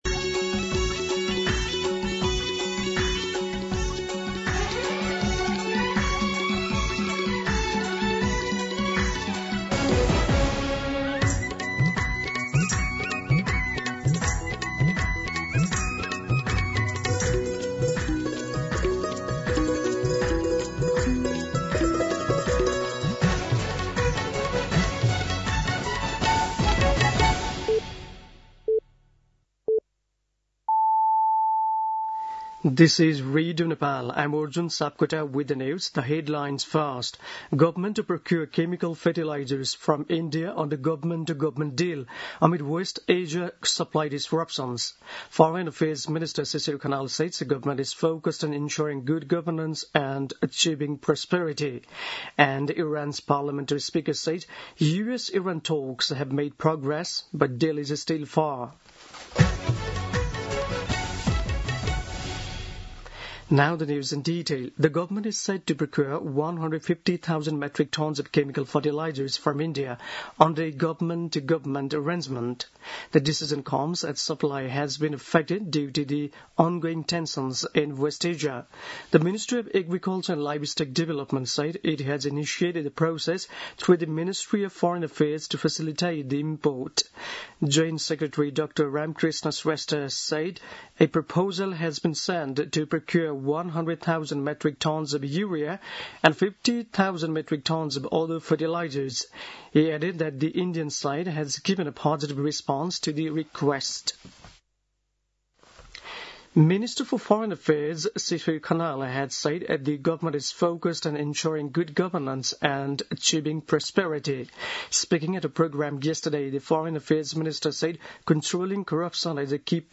दिउँसो २ बजेको अङ्ग्रेजी समाचार : ६ वैशाख , २०८३
2pm-English-News-1-6.mp3